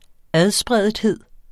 Udtale [ ˈaðˌsbʁεˀdˌheðˀ ]